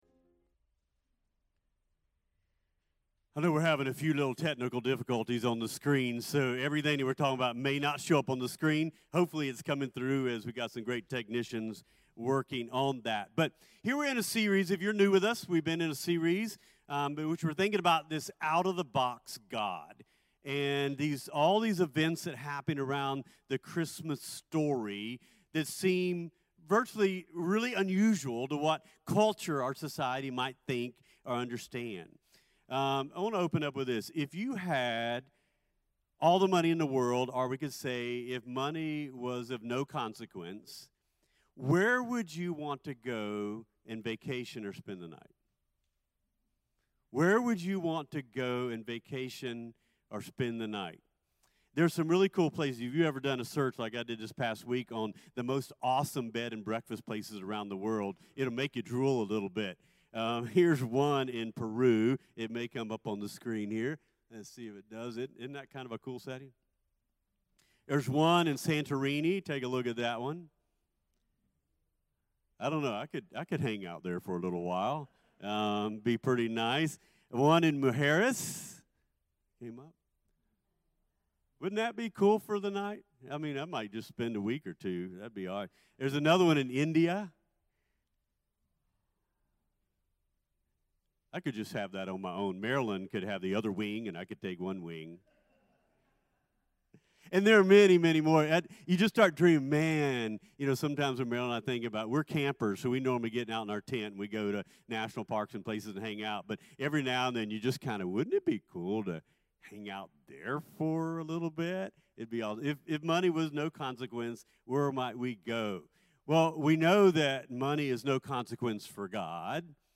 CTK-Dec-18-Clipped-Sermon-.mp3